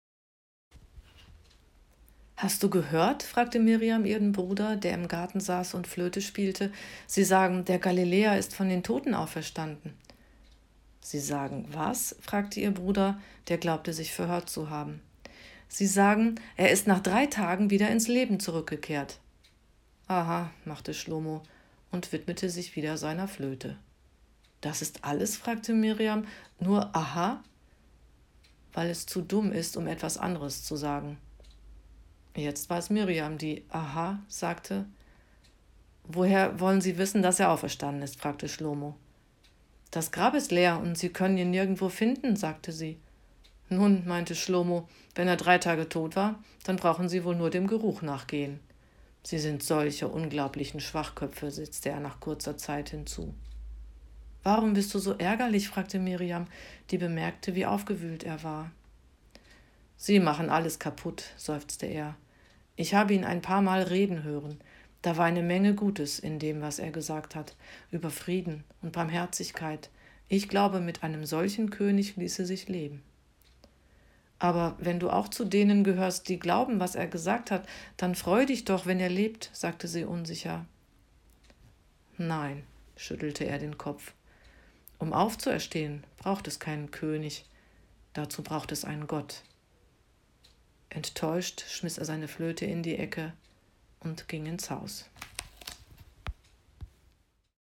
Dialog I